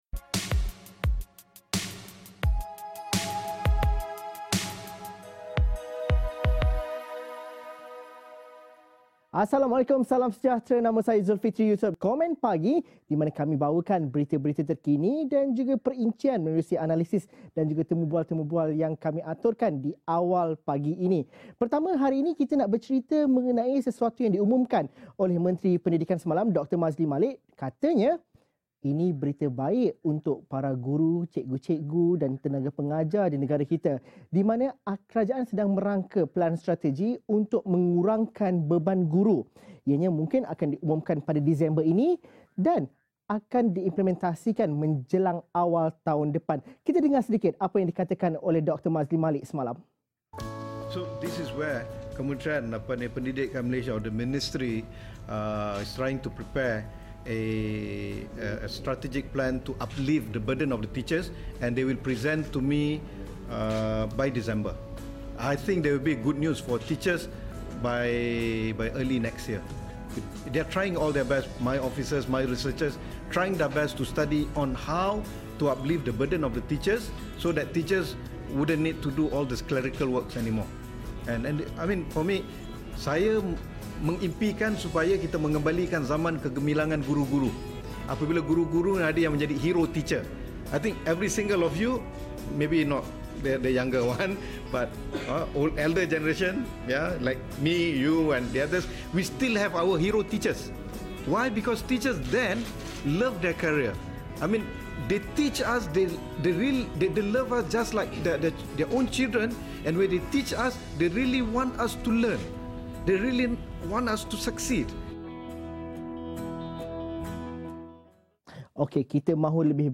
Diskusi mengenai cadangan Menteri Pendidikan, Dr Maszlee Malik yang mahukan beban guru dikurangkan menjelang awal tahun depan.